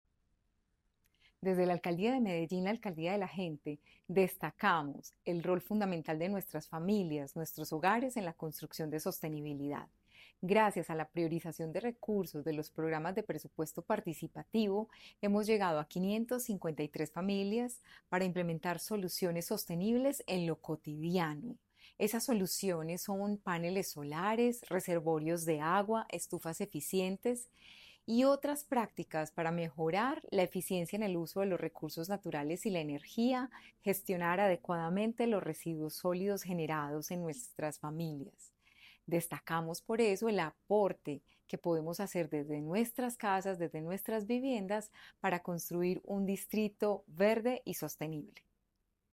Declaraciones secretaria de Medio Ambiente, Marcela Ruiz Saldarriaga
Declaraciones-secretaria-de-Medio-Ambiente-Marcela-Ruiz-Saldarriaga.mp3